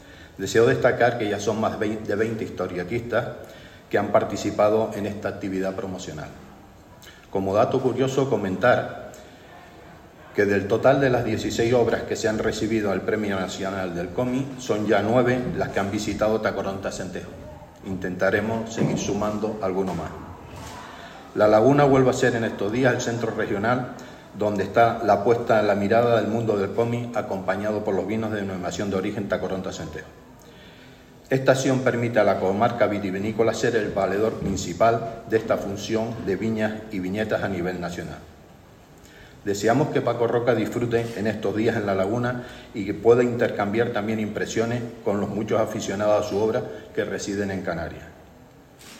Intervención